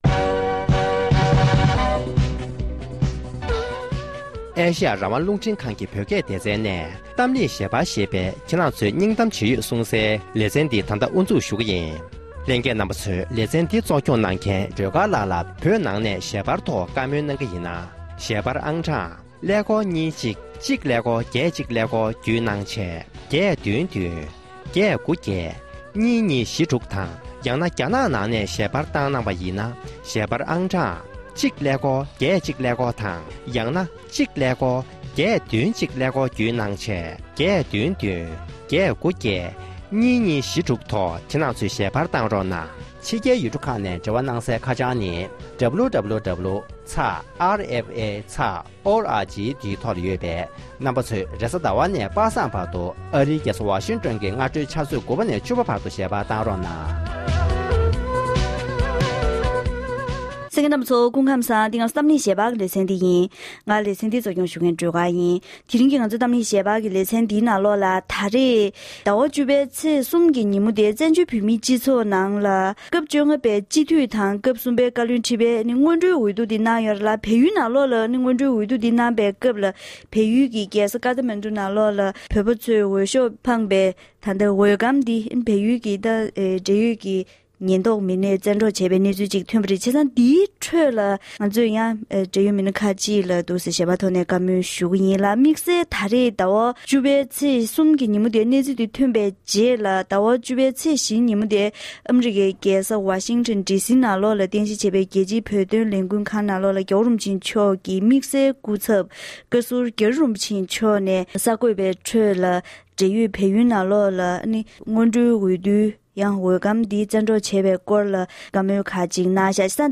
དམིགས་བསལ་སྐུ་ཚབ་བཀའ་ཟུར་རྒྱ་རི་རིན་པོ་ཆེ་མཆོག་ནས་གསར་འགོད་པ་གནད་དོན་དེའི་ཐོག་འགྲེལ་བརྗོད་གནང་བ།